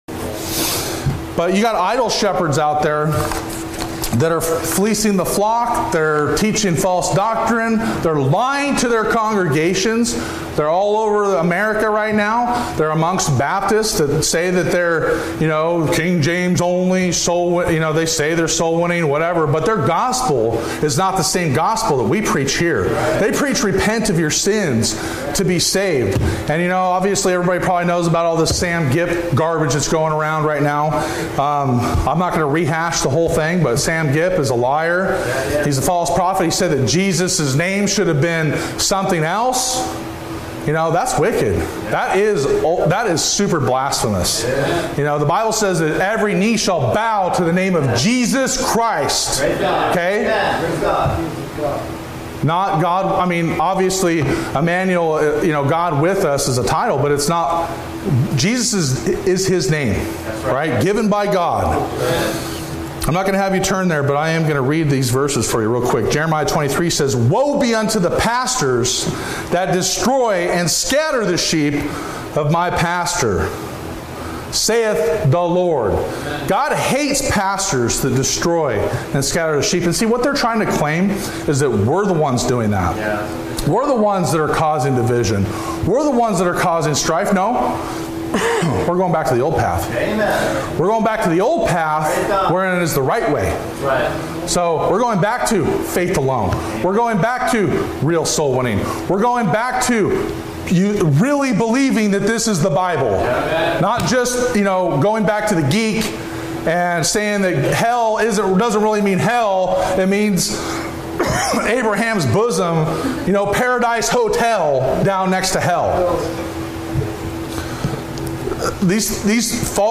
Every NIFB Sermon and More